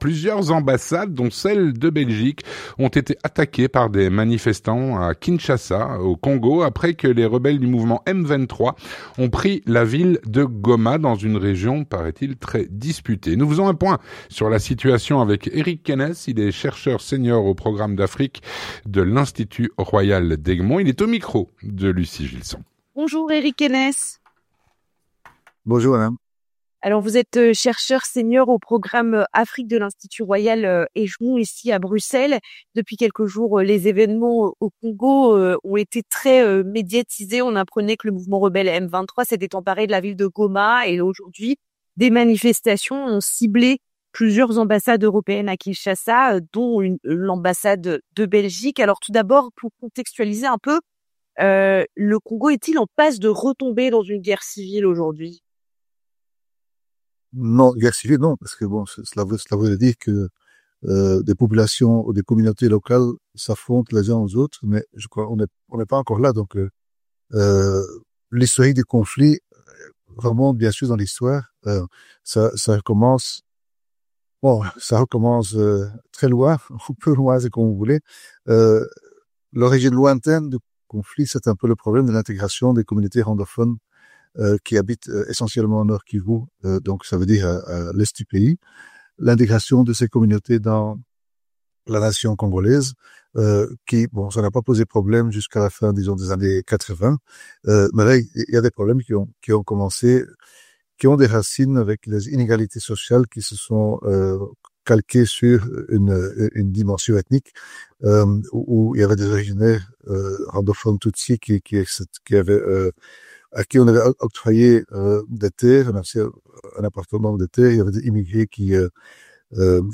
L'entretien du 18H - Le pouvoir congolais fait face à une rebellion armée extrêmement violente.